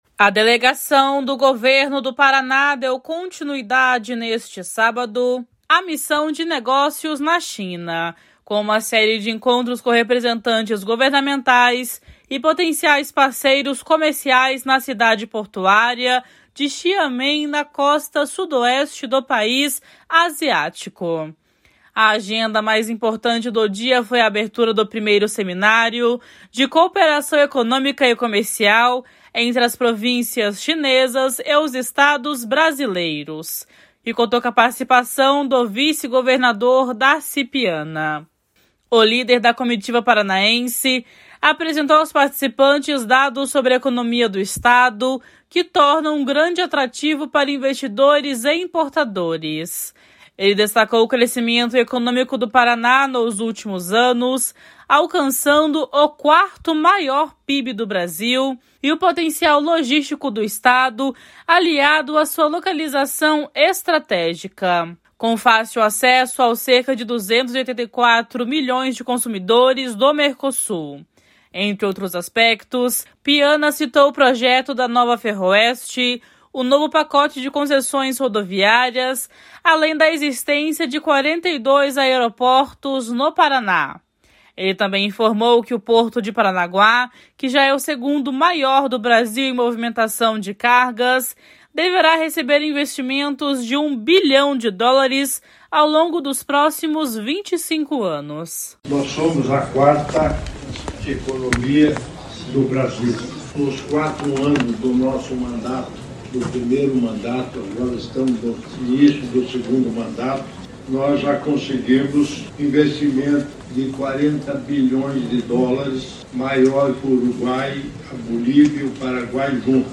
// SONORA DARCI PIANA //
// SONORA MARCOS BEZERRA GALVÃO //